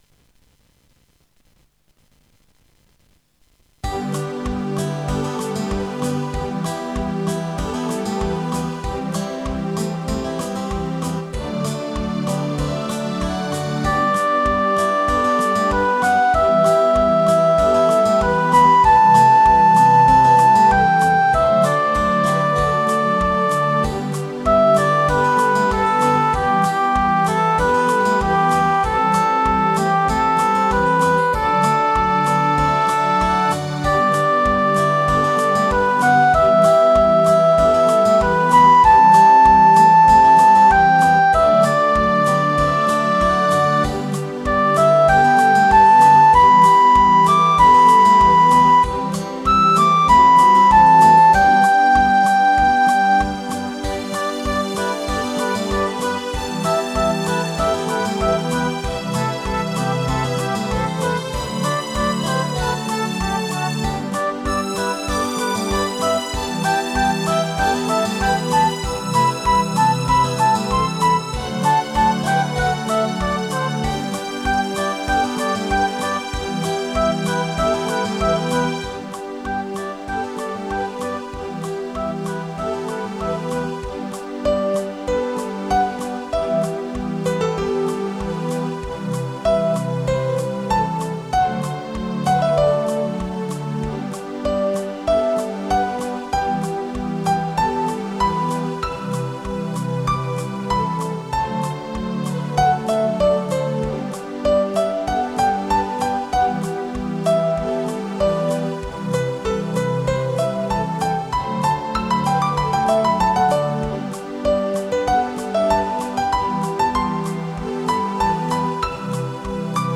４．個人の作った曲（Desktop Music)　の紹介